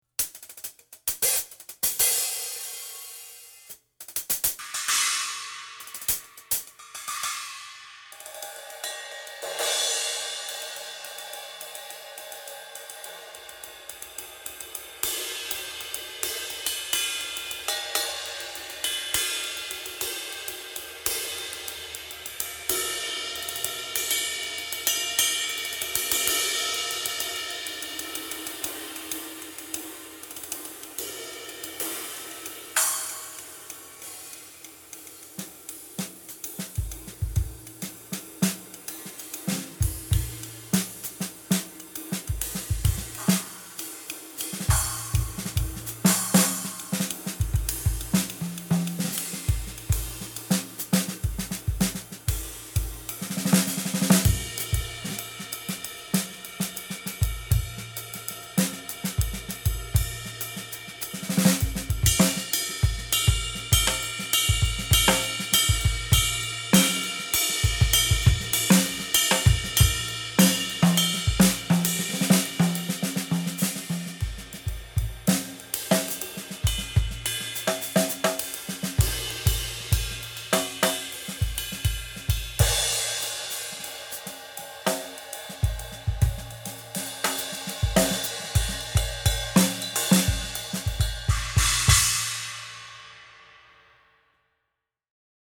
horacio flat ride istanbul Medium Ride Istanbul Mehmet Horacio Hernandez Cymbals Hi-Hat Istanbul Mehmet Horacio Hernandez Cymbals Splash Istanbul Mehmet Horacio Hernandez Cymbals
The result, as you’ll read, is some of the tastiest cymbals I’ve heard in a while.